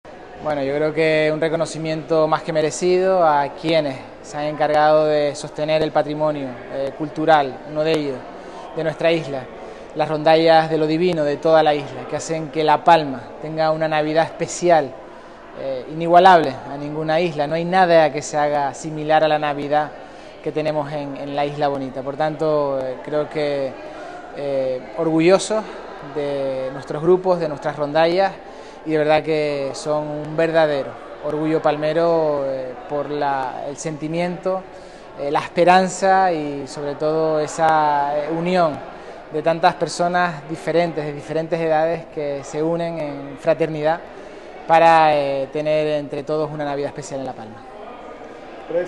El Cabildo de La Palma, en sesión plenaria solemne celebrada en el Teatro Circo de Marte, otorgó a ‘Lo Divino’ la Medalla de la Isla por conservar una tradición que recientemente ha cumplido 75 años de vida.
Declaraciones audio Mariano Zapata.mp3